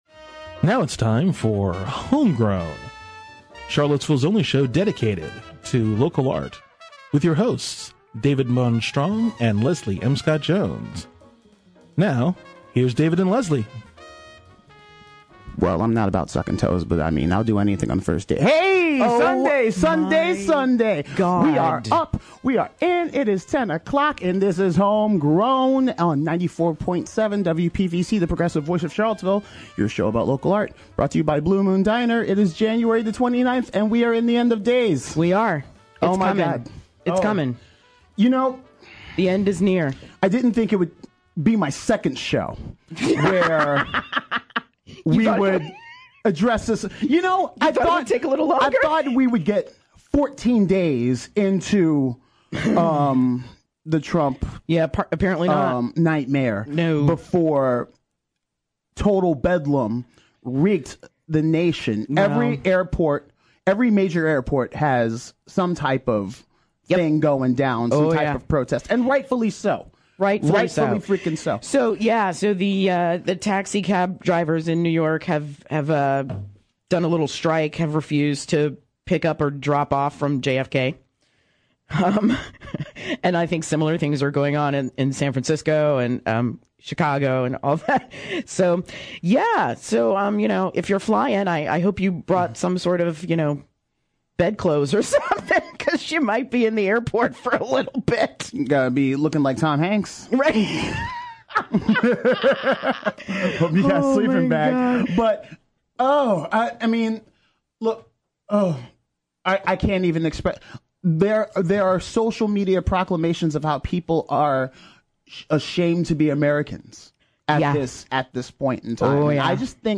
Fortunately he’s got a couple of great guests.